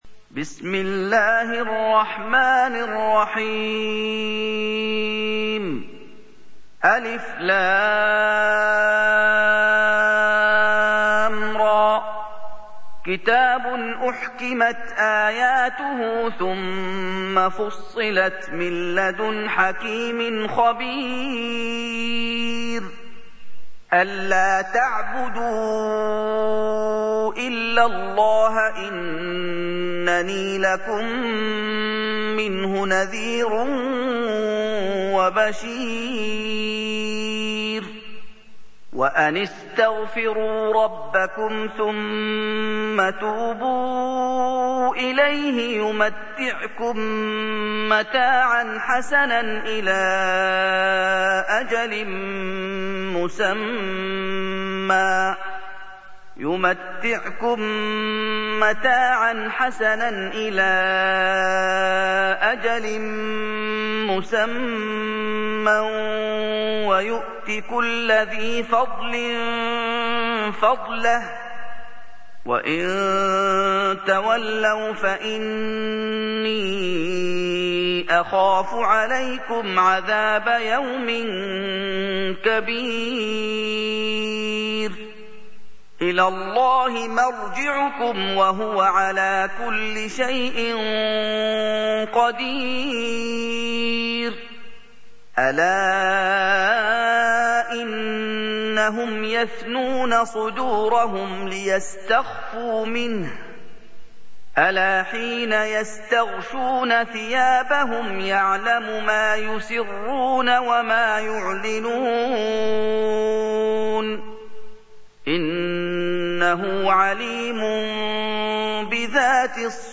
سُورَةُ هُودٍ بصوت الشيخ محمد ايوب